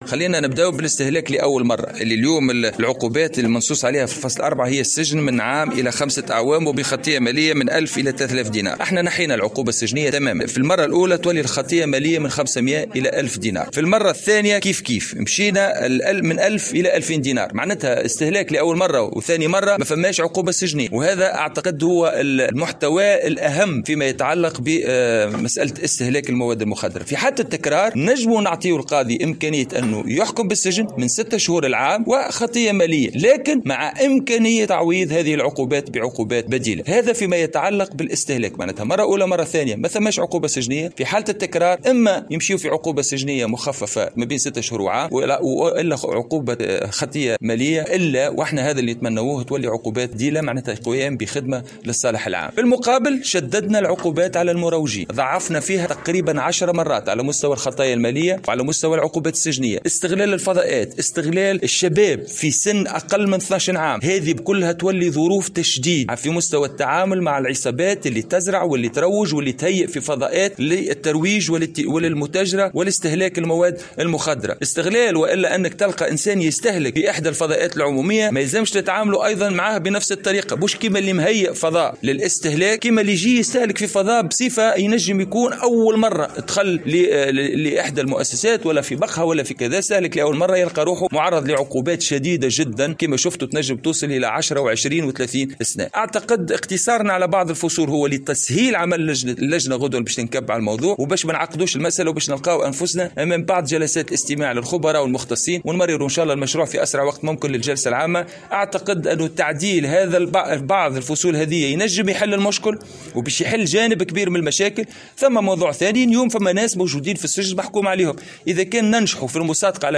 عقدت كتلة الاصلاح الوطني اليوم الخميس 4 فيفري 2021 ندوة صحفية بالبرلمان لتقديم مشروع مبادرة تشريعية تتعلق بالقانون 52 المتعلق بإستهلاك المخدرات.
وأكد رئيس الكتلة حسونة الناصفي في تصريح لمراسل الجوهرة "اف ام" أن تعديل القانون يقترح تشديد العقوبات السجنية والمالية للمروجين الذين يستغلون الأطفال في الترويج والبيع حسب تعبيره.